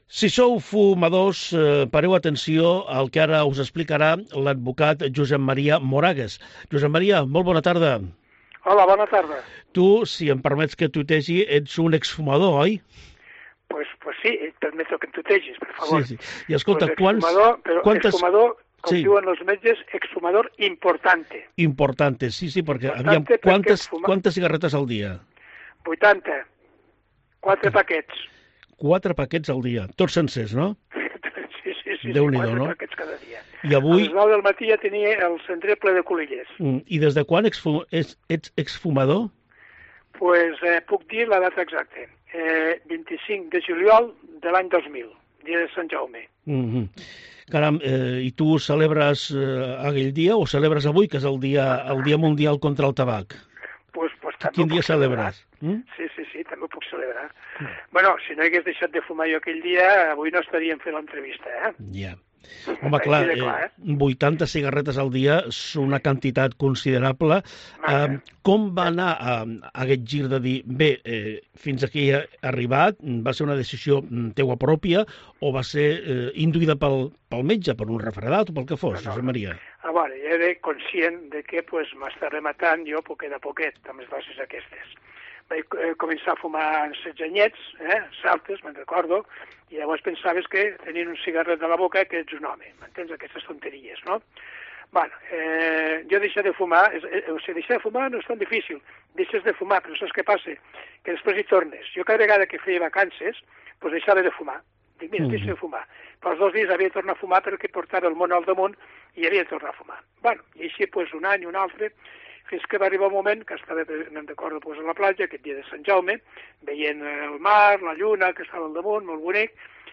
Desgarrador testimonio de un exfumador que explica como dejó su adicción al tabaco